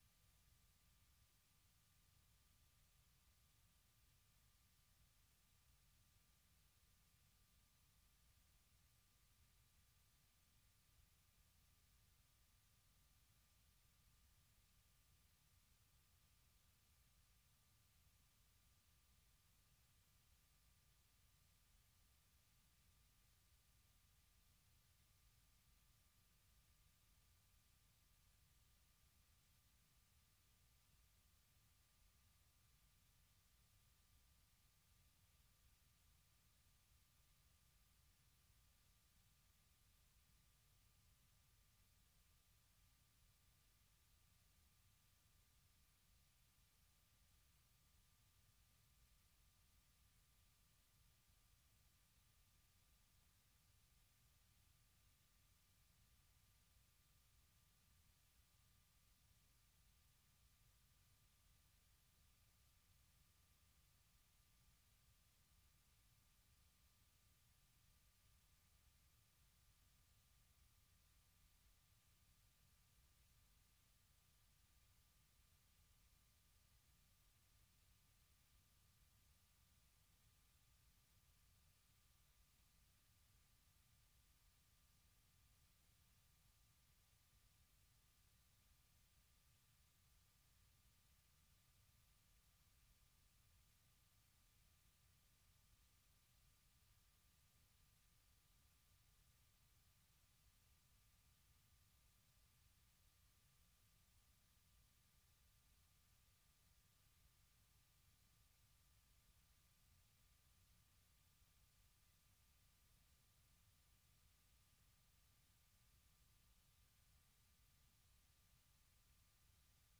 Allocution du président Donald Trump devant la session conjointe du Congrès